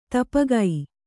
♪ tapagai